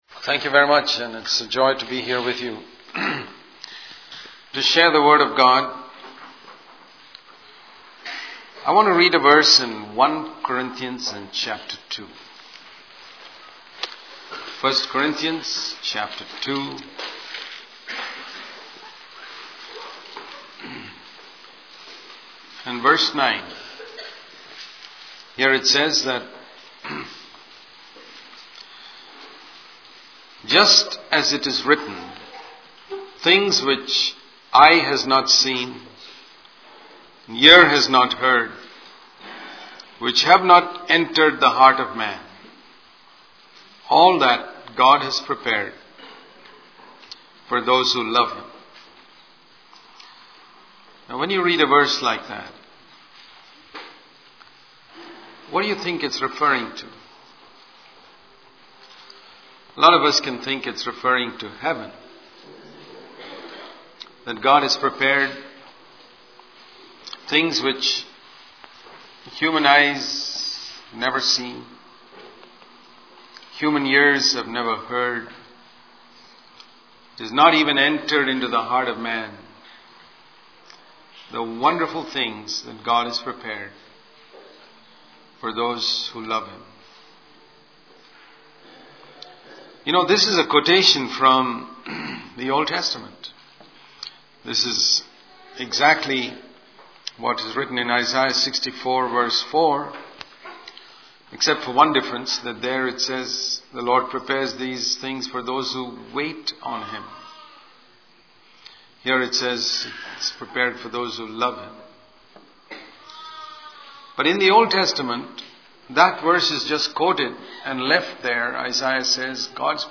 In this sermon, the speaker emphasizes the importance of overcoming temptation in the power of the Holy Spirit. He highlights that Jesus was tempted for 40 days before facing the final three temptations mentioned in chapter 4 of the Bible.